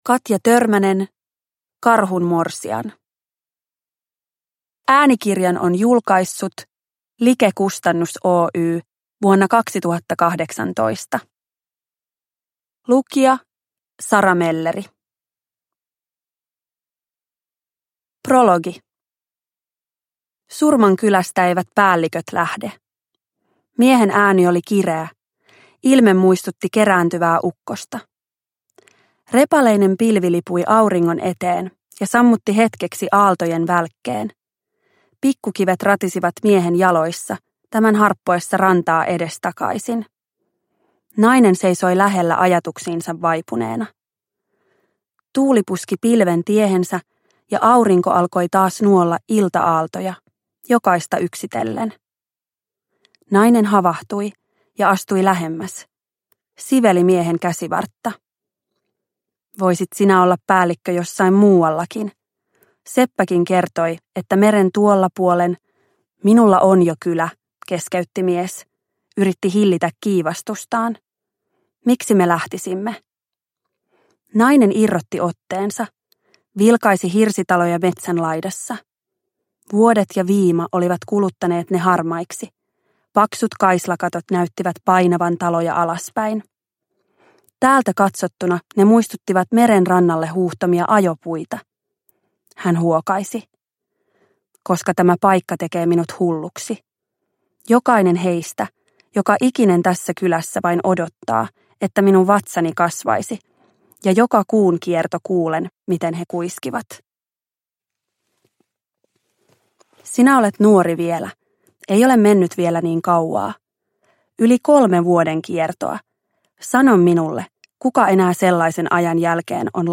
Karhun morsian – Ljudbok – Laddas ner